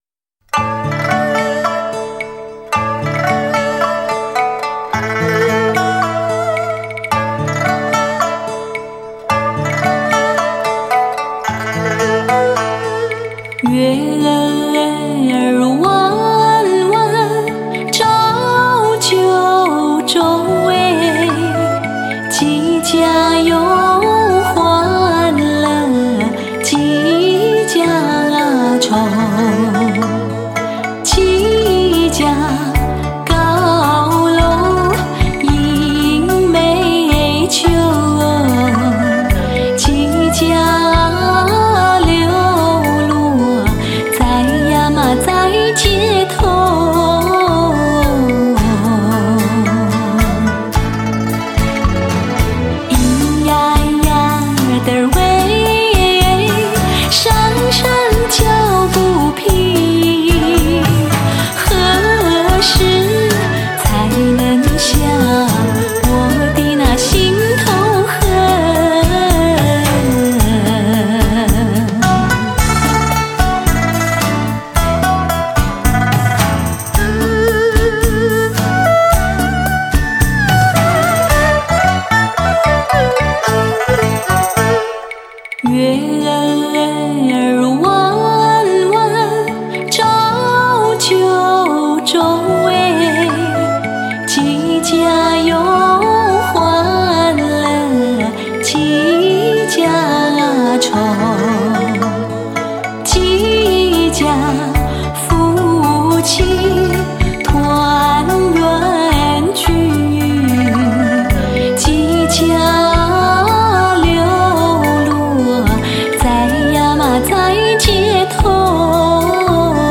甜嗓声音情人 金曲风华毕露